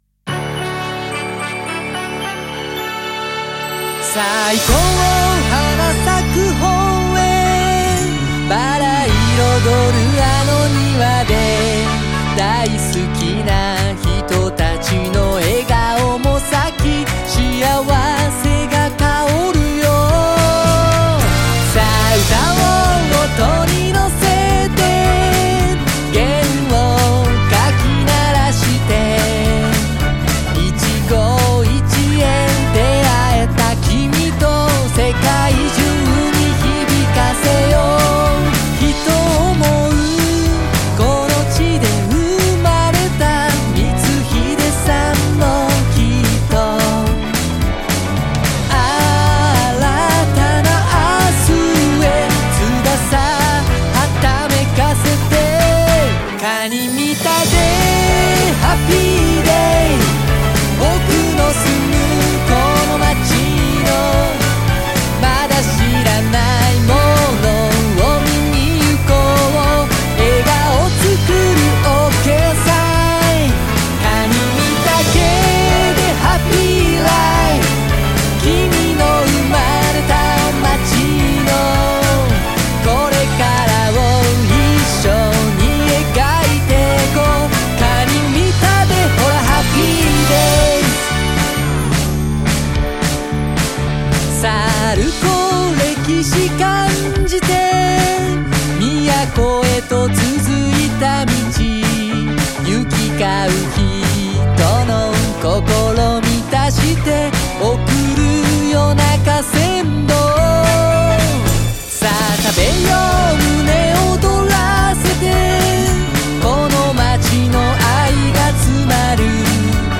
歌詞入りver